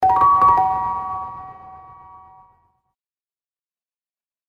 ピアノの音色の通知音。